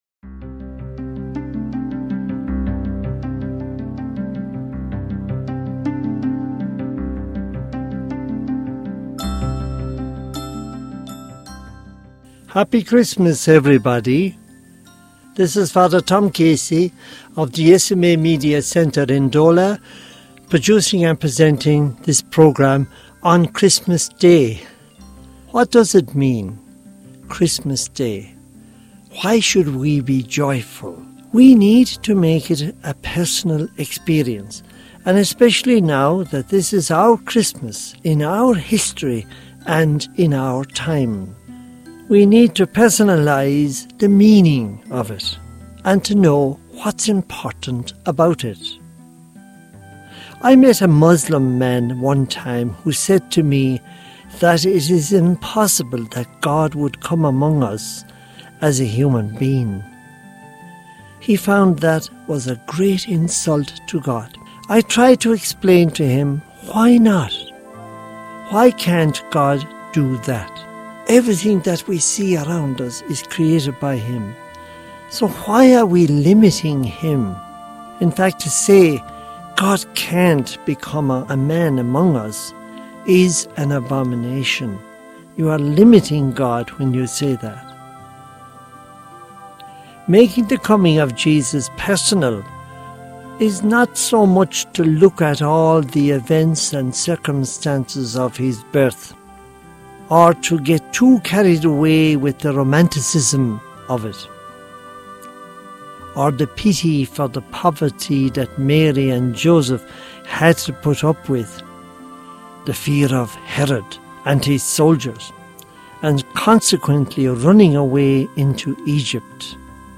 Homily for the Feast of the Nativity of the Lord, Night Time Mass, Year A, 2025 | Society of African Missions